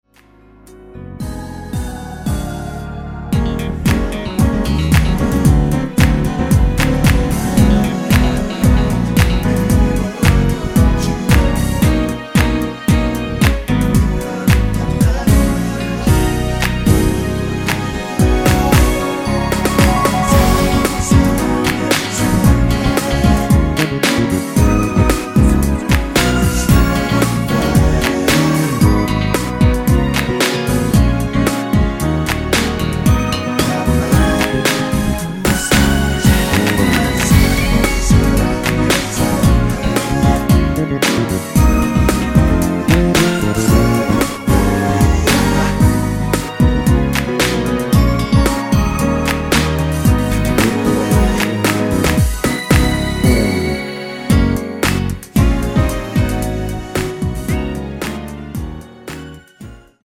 코러스 포함된 MR 입니다.(미리듣기 참조)
F#
앞부분30초, 뒷부분30초씩 편집해서 올려 드리고 있습니다.
중간에 음이 끈어지고 다시 나오는 이유는